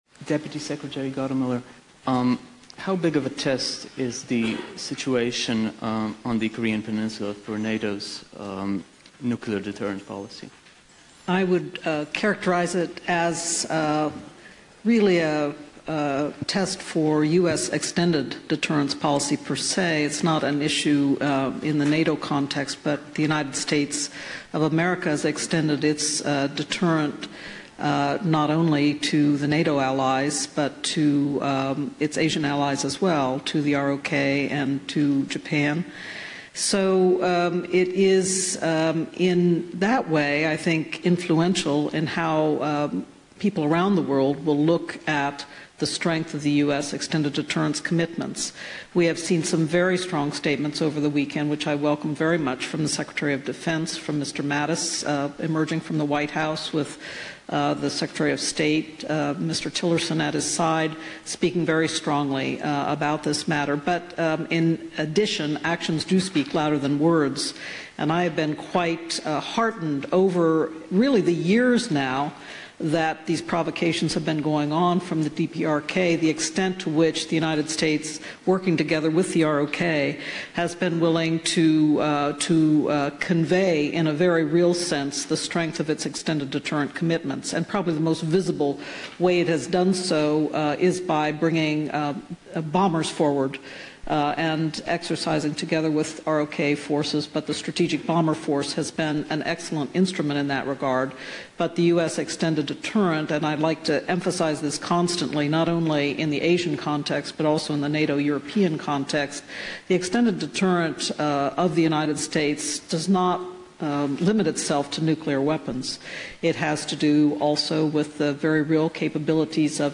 Remarks by NATO Deputy Secretary General Rose Gottemoeller
at the panel discussion ''Global nuclear governance: Quo Vadis?'' at the Bled Strategic Forum (BSF), Slovenia